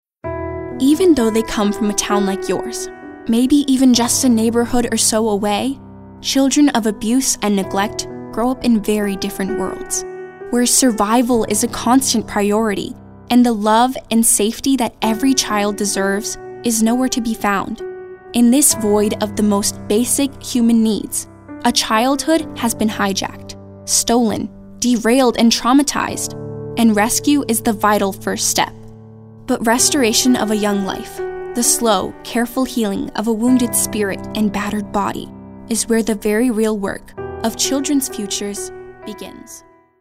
caring, compelling, concerned, confessional, genuine, inspirational, mellow, motivational, narrative, real, serious, sincere, storyteller, teenager, thoughtful, warm, young, younger